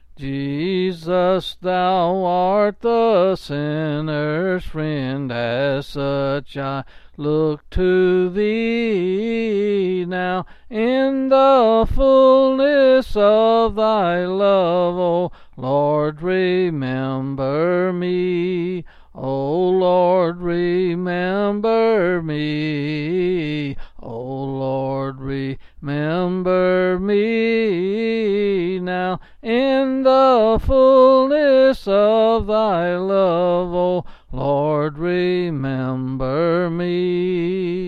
Quill Pin Selected Hymn
C. M.